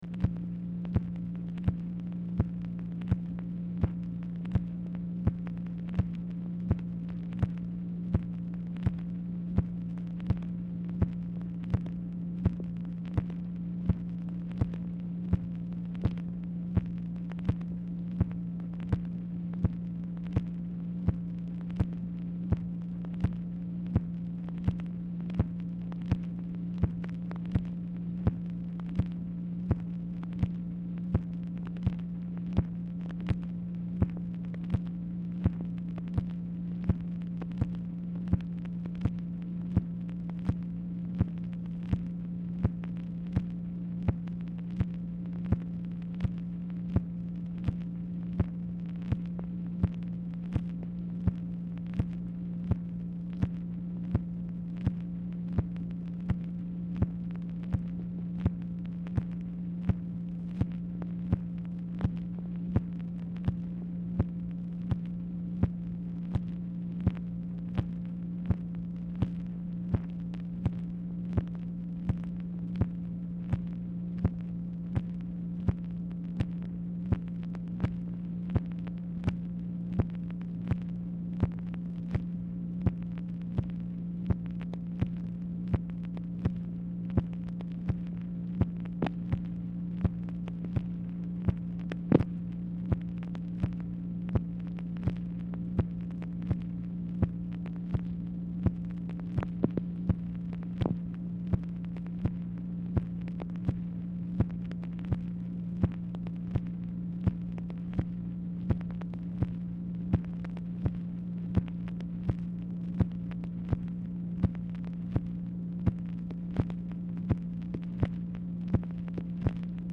Telephone conversation # 9879, sound recording, MACHINE NOISE, 3/10/1966, time unknown | Discover LBJ
Format Dictation belt
White House Telephone Recordings and Transcripts Speaker 2 MACHINE NOISE